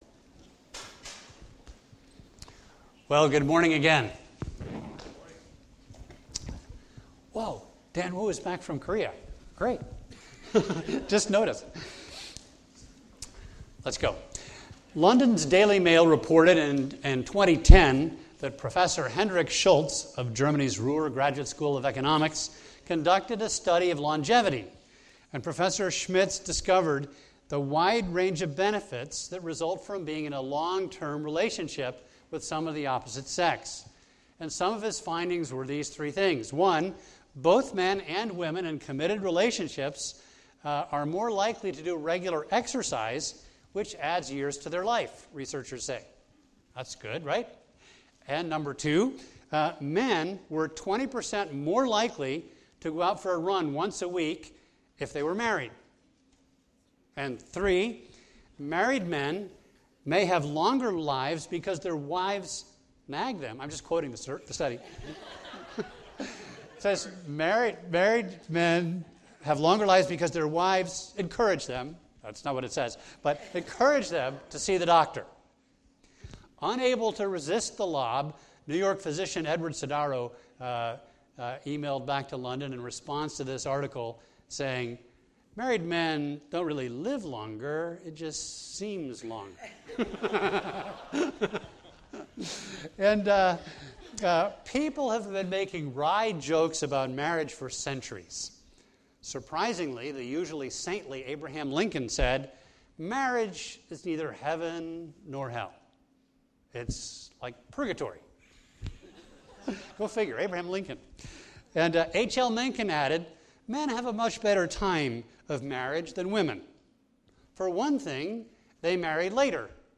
A message from the series "The Meaning of Marriage."